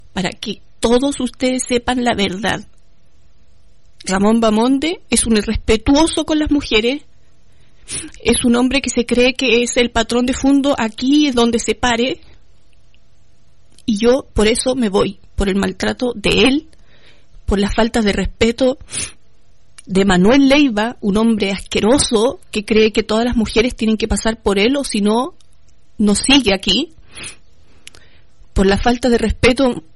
La denuncia fue realizada en vivo durante un programa de la propia emisora.